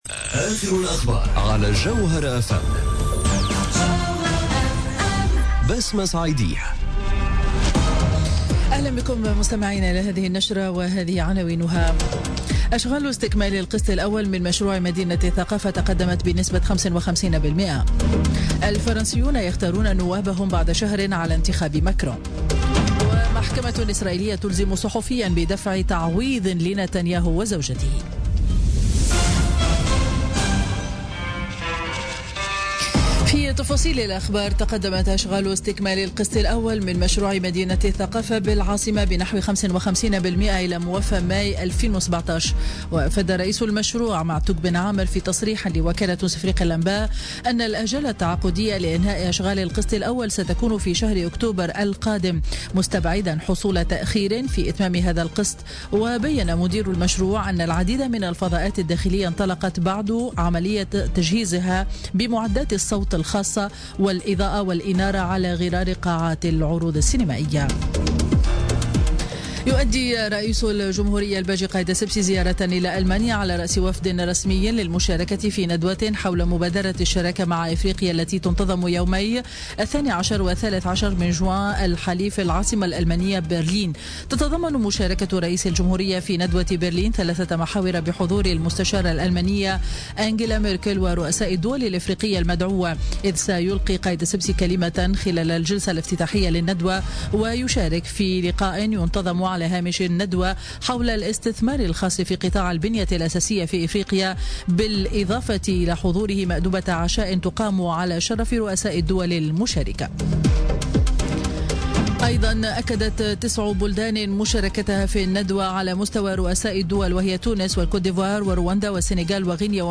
نشرة أخبار منتصف النهار ليوم الأحد 11 جوان 2017